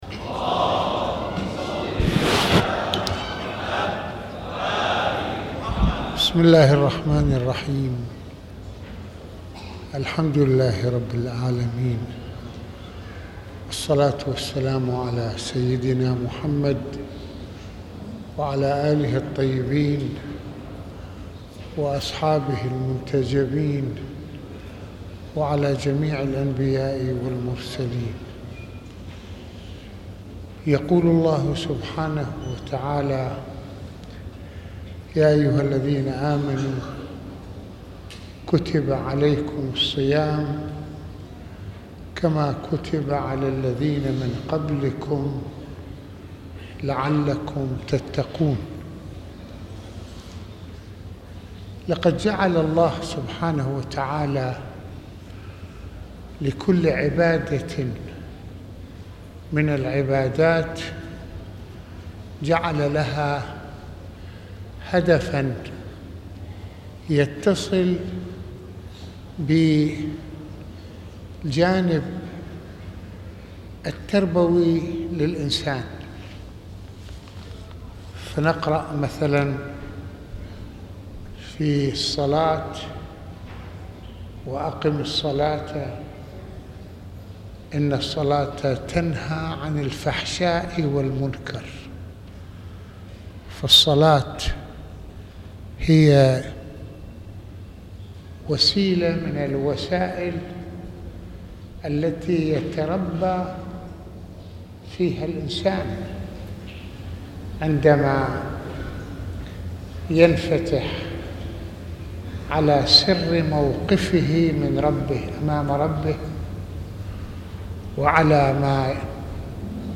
العبادة مدرسة لتزكية الإنسان | محاضرات رمضانية